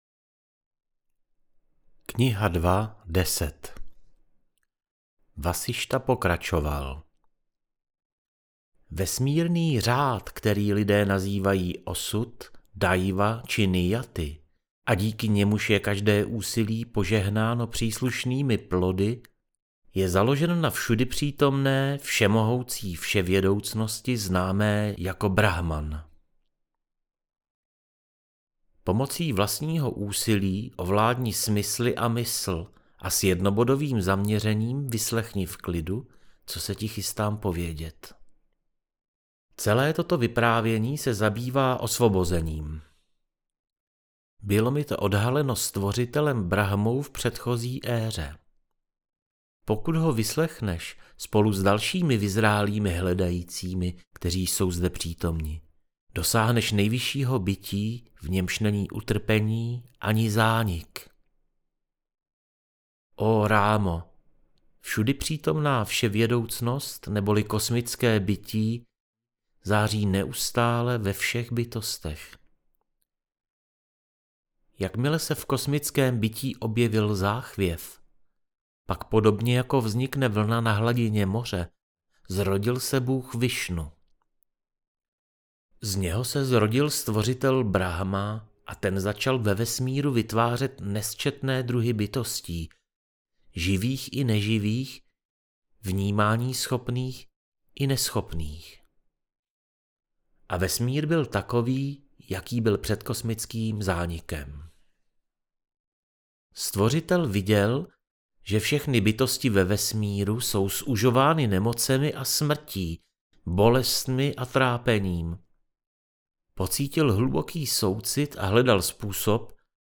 JÓGA VÁSIŠTHA - AUDIOKNIHA